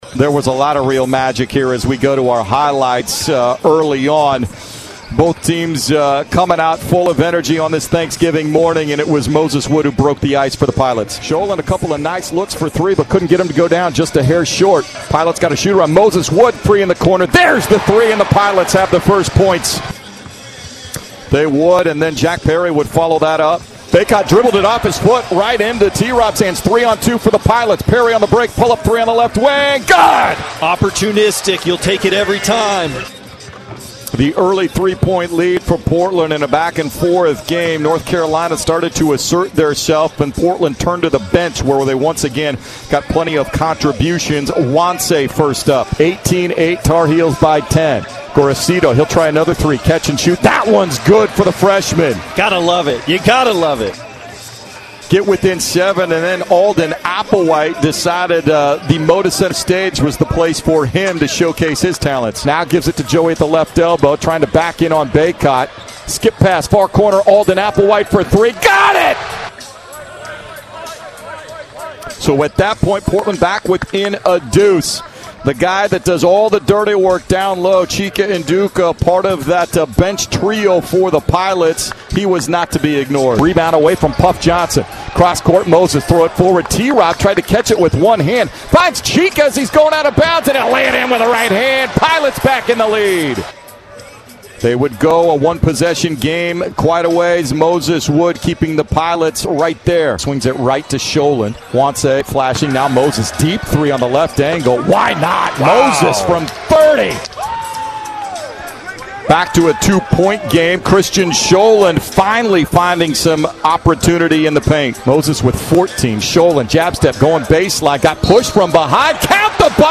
November 24, 2022 Portland came up just short to No. 1 North Carolina to open the Phil Knight Invitational on Thursday, Nov. 24 at Moda Center. Post-game radio show courtesy of 910 ESPN Portland (KMTT).